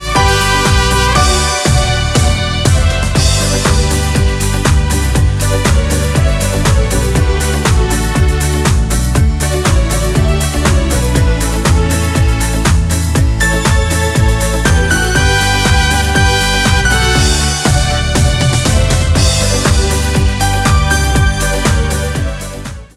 Hörprobe Karaoke-Version:
• Emotionaler Liedtext und eingängige Melodie